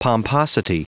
Prononciation du mot pomposity en anglais (fichier audio)
Prononciation du mot : pomposity
pomposity.wav